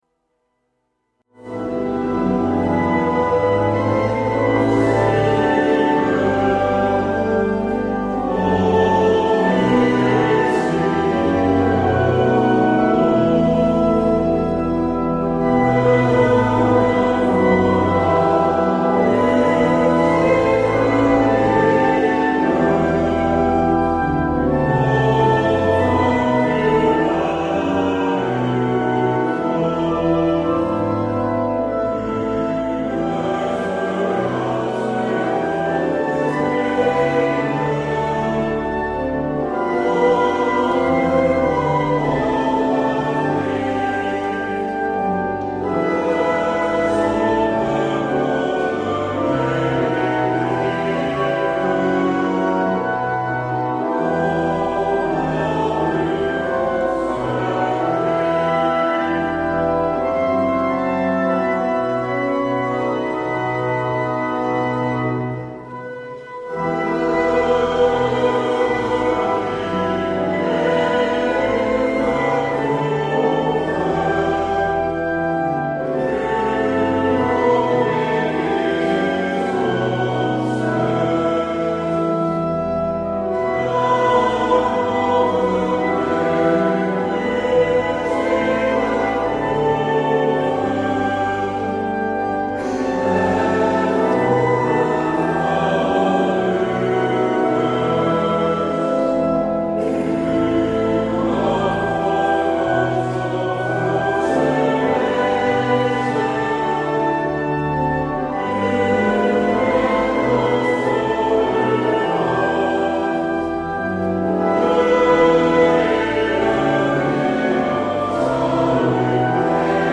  Luister deze kerkdienst hier terug
Het openingslied is: Hemelhoog 386:1, 2 en 5. Als slotlied hoort u: Hemelhoog 450: 1 en 2.